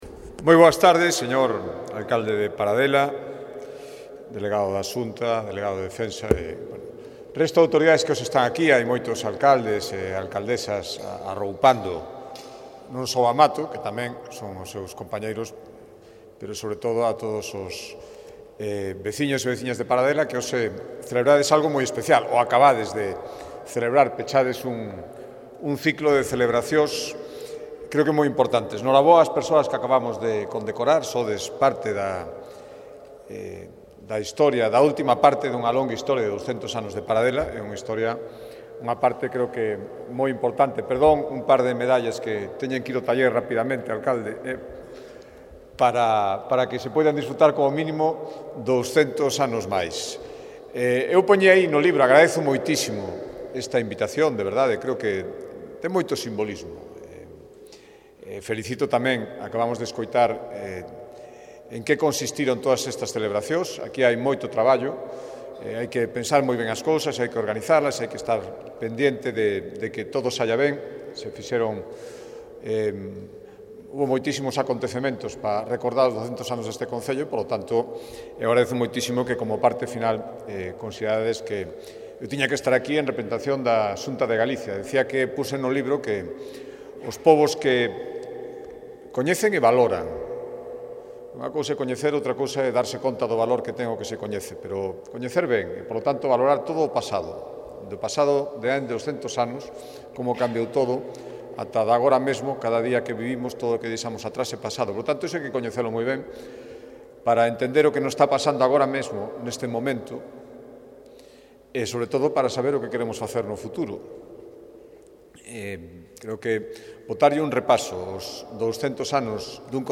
Discurso do Presidente da Xunta de Galicia, don Alfonso Ruedo (mp3)